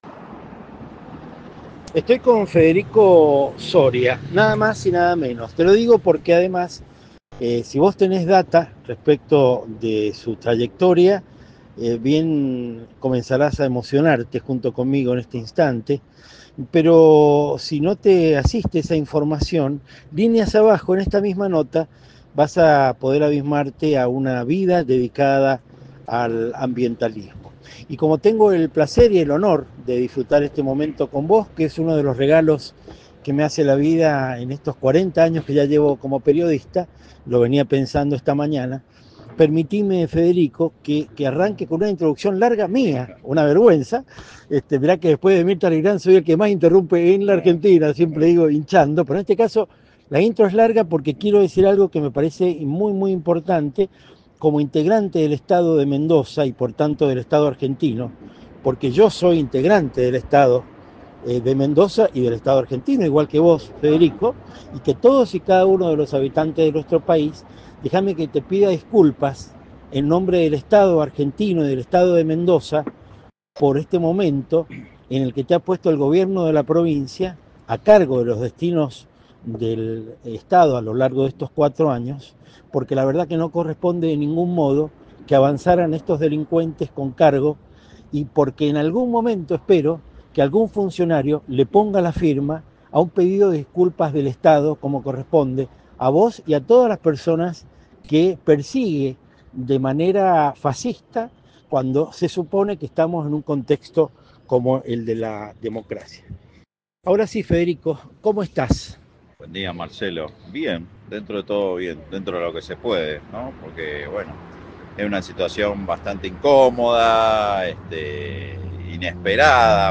Mientras compartíamos un café fuimos cruzando algunos datos e información, a lo que siguió la charla que vos también podrás disfrutar a tris de un clic, líneas abajo.